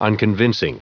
Prononciation du mot unconvincing en anglais (fichier audio)
Prononciation du mot : unconvincing